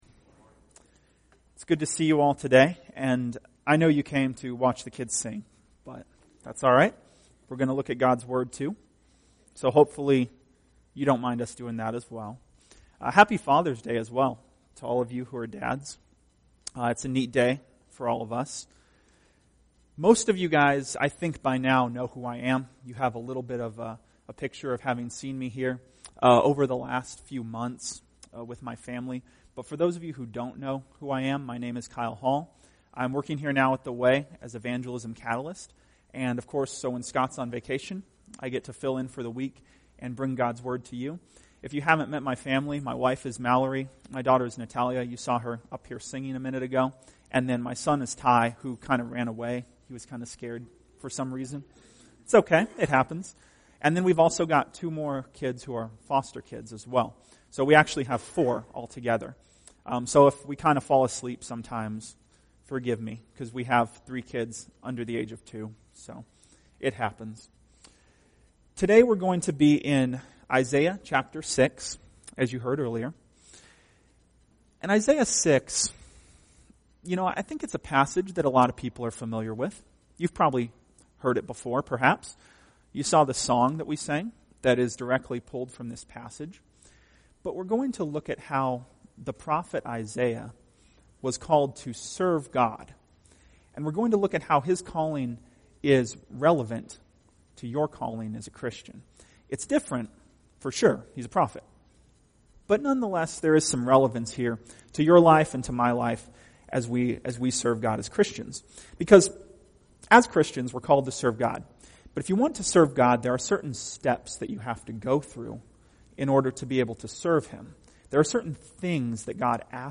Tagged with Sunday Sermons